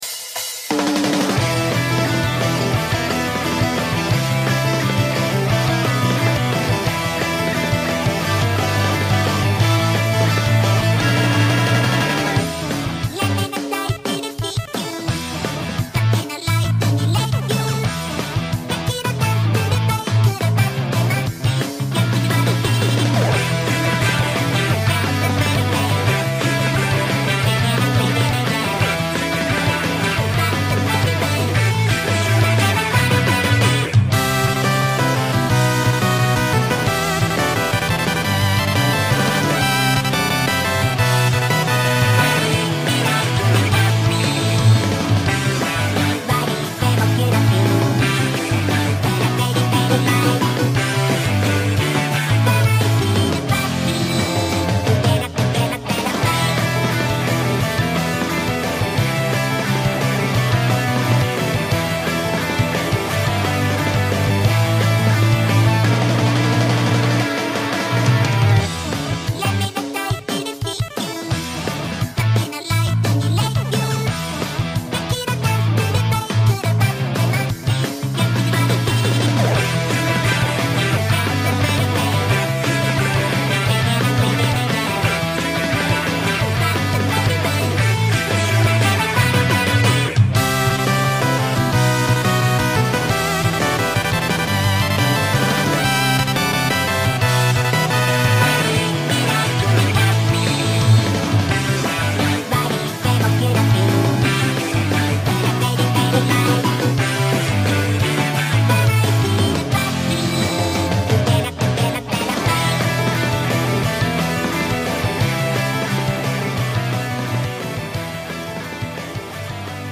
8-bit type sound